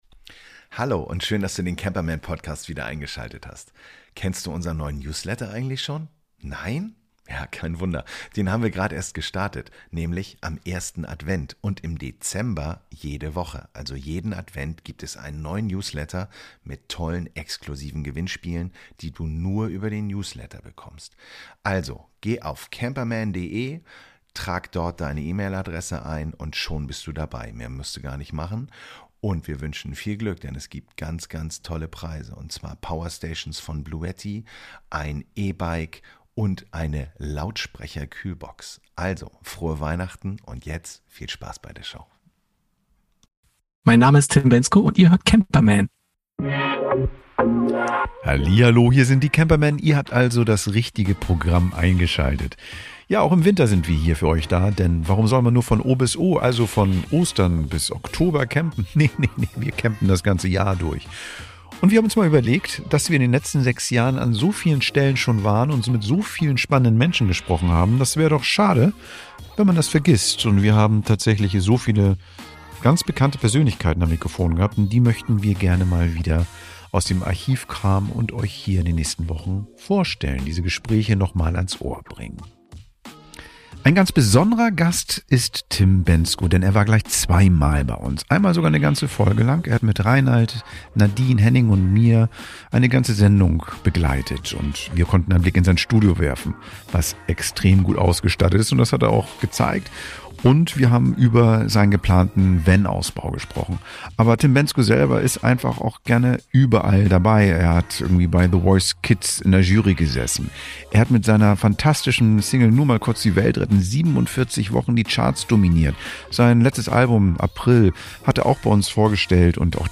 In dieser Folge unserer "VIP-Extras" veröffentlichen wir unsere Interviews mit dem Sänger Tim Bendzko.